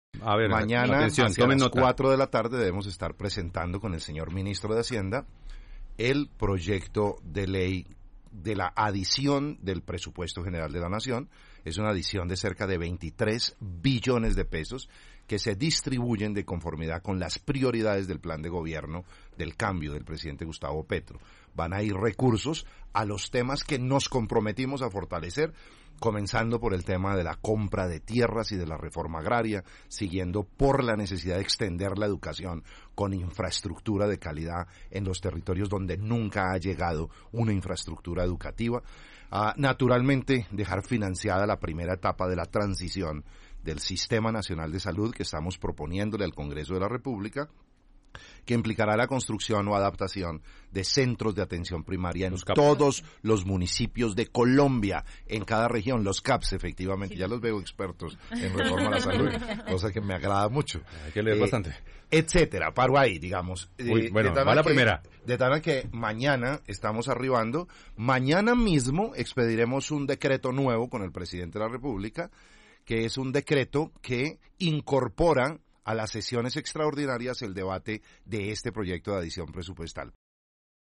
El Ministro del Interior, Alfonso Prada, confirmó este jueves en ‘Colombia Hoy Radio’ que este viernes 17 de febrero estará radicando, junto con el titular de la cartera de Hacienda, José Antonio Ocampo, el proyecto de ley de adición al Presupuesto General de la Nación por cerca de 23 billones de pesos.
MINISTRO DEL INTERIOR ALFONSO PRADA GIL, sobre presupuesto nacional.